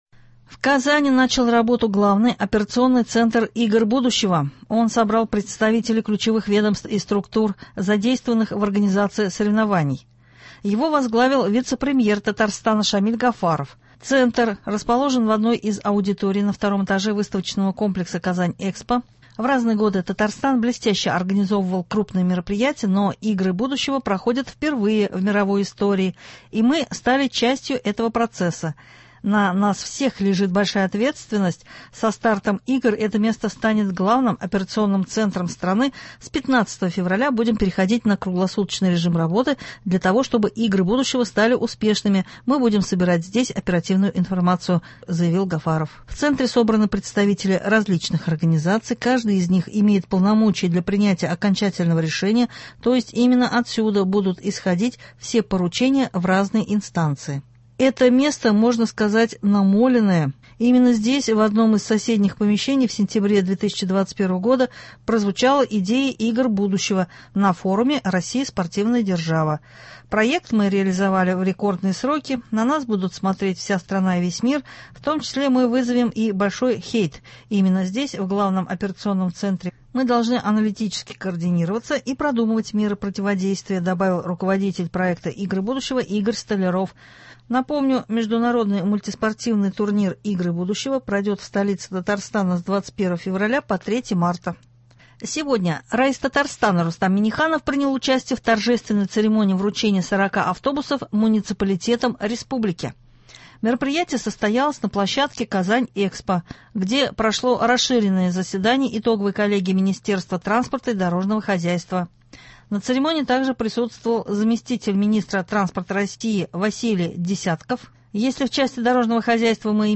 Новости (1.02.24)